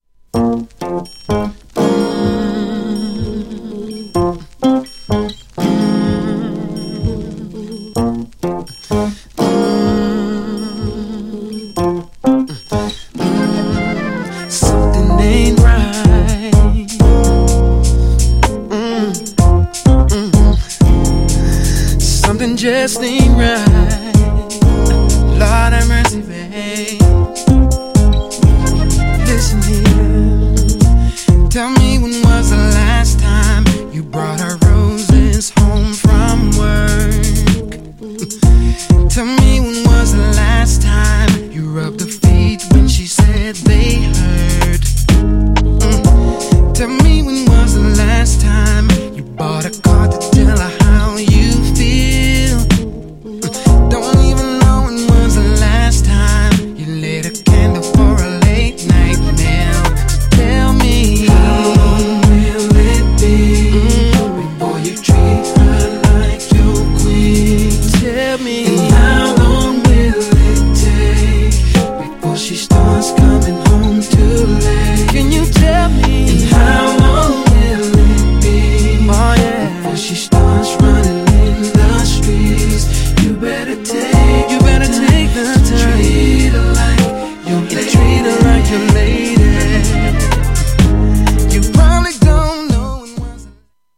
最上級SLOWナンバー!!
GENRE R&B
BPM 66〜70BPM